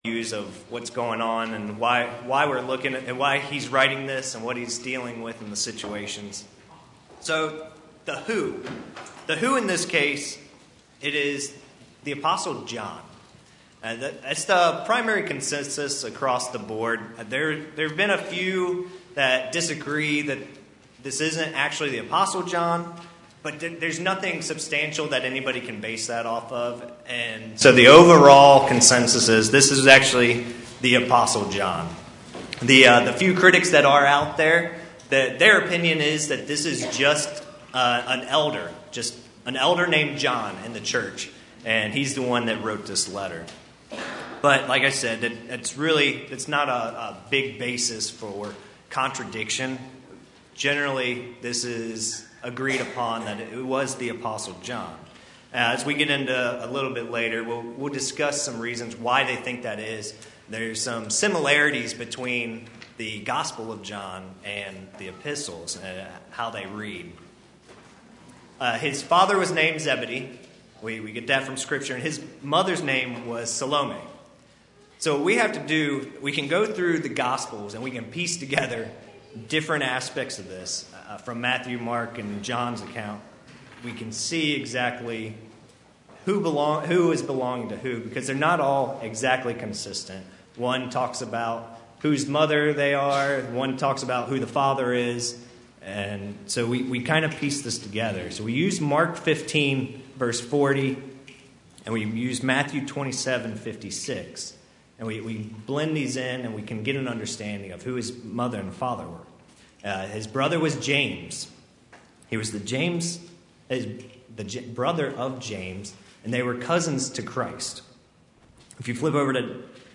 Bible Study of 1 John